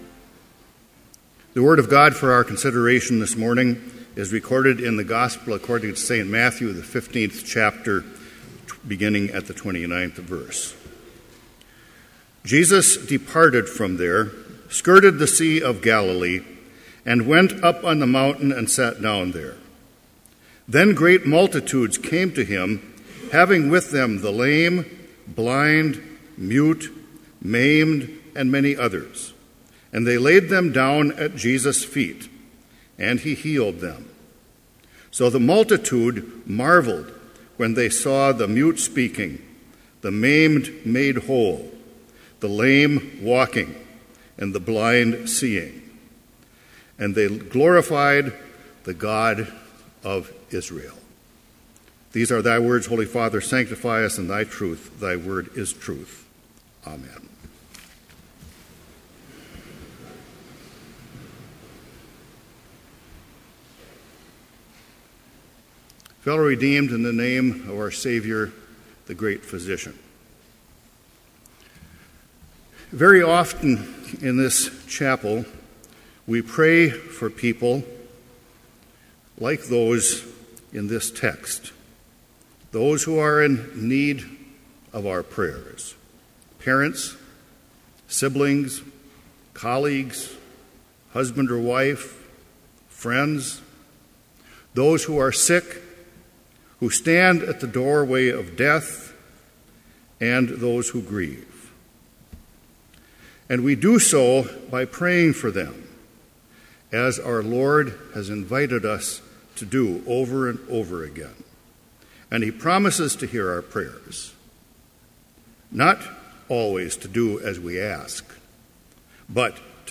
Complete Service
Order of Service
• Hymn 50, Before Your Awesome Majesty
• Homily